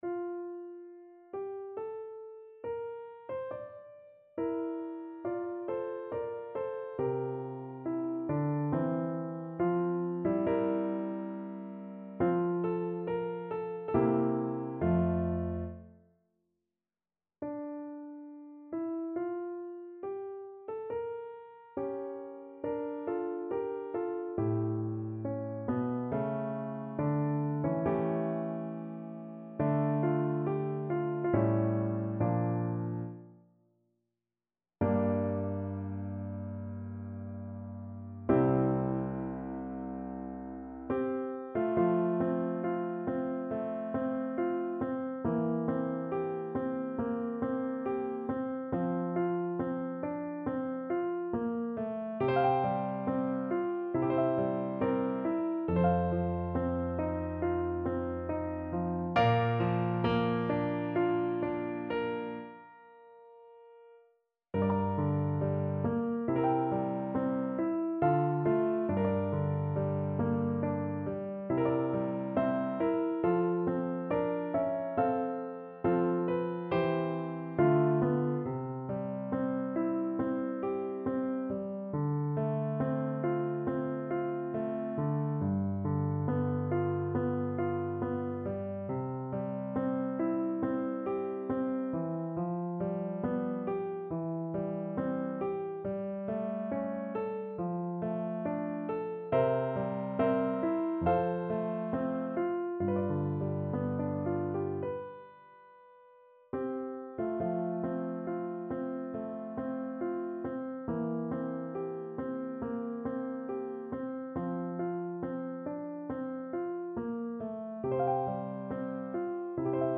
Play (or use space bar on your keyboard) Pause Music Playalong - Piano Accompaniment Playalong Band Accompaniment not yet available reset tempo print settings full screen
Larghetto (=80) =69
F major (Sounding Pitch) G major (Clarinet in Bb) (View more F major Music for Clarinet )
Classical (View more Classical Clarinet Music)